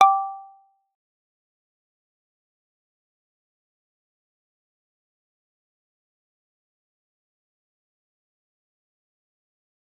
G_Kalimba-G5-f.wav